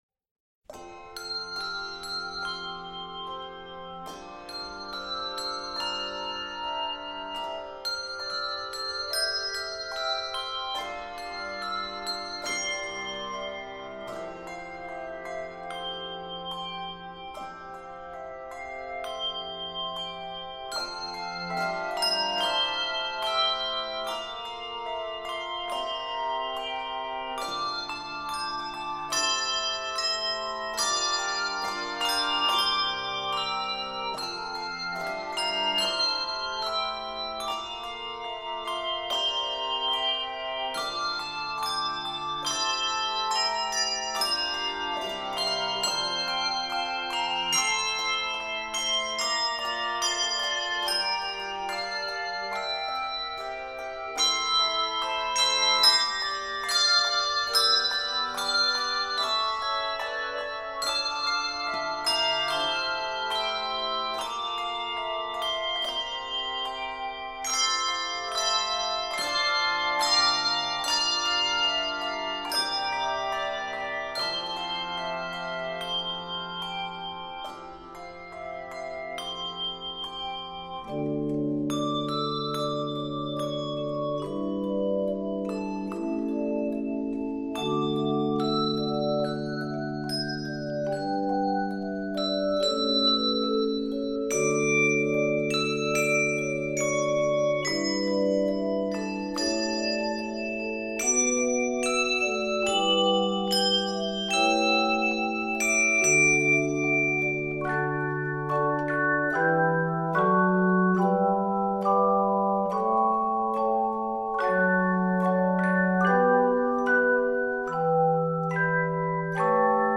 joyful arrangement